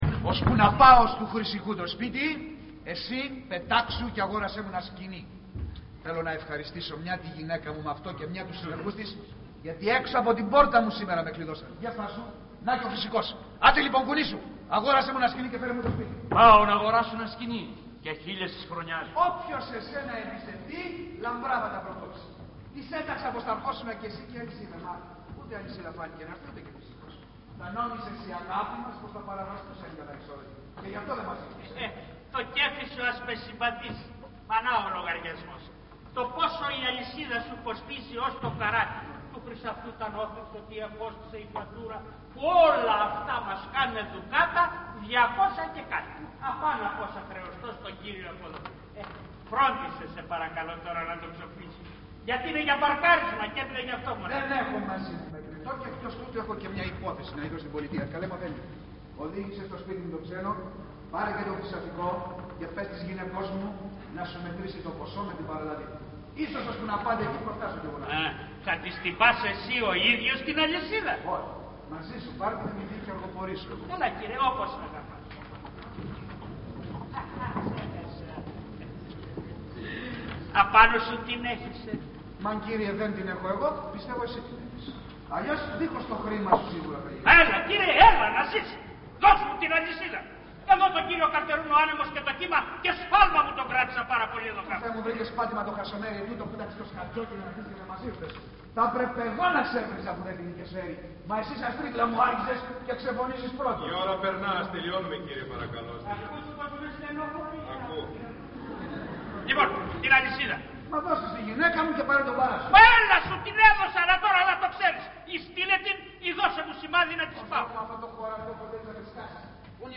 Ηχογράφηση Παράστασης
Αποσπάσματα από την παράσταση